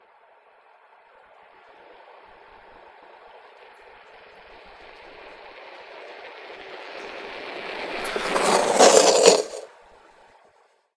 Звук подъезжающего электросамоката